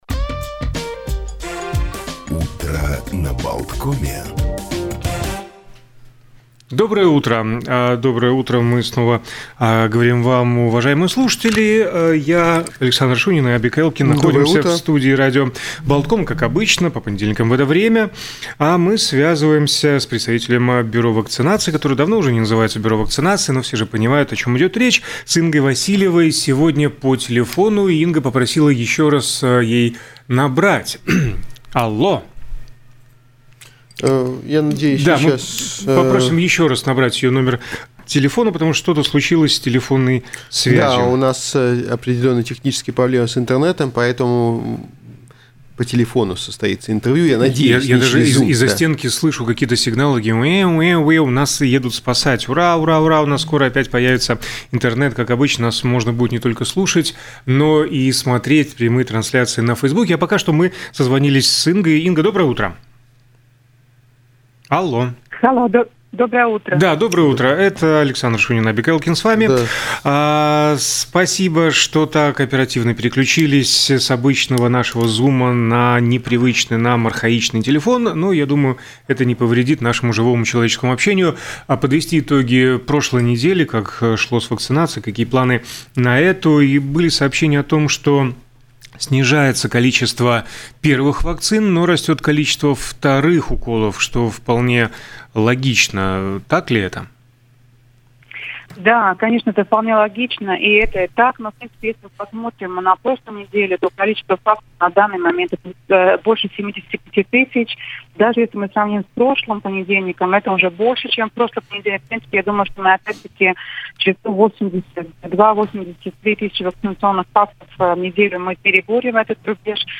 Об этом в эфире радио Baltkom рассказала представитель отдела проекта